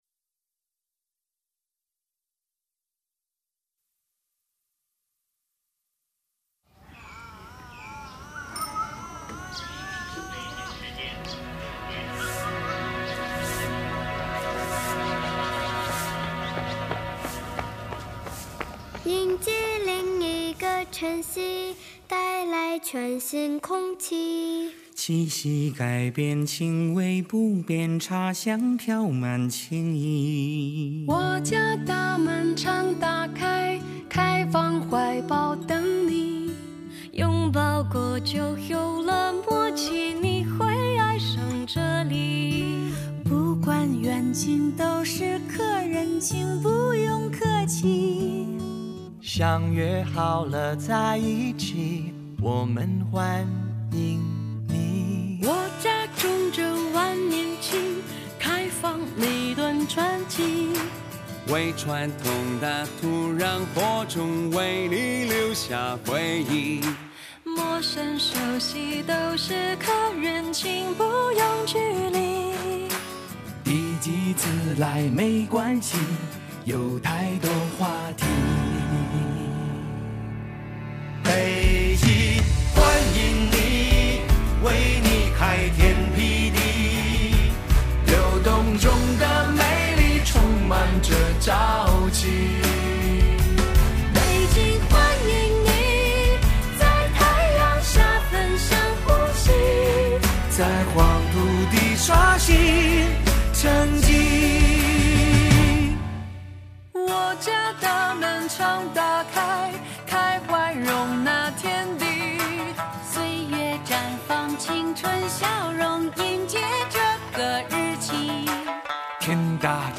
这首歌确实很动听，融入了不少中国风的元素，很不错。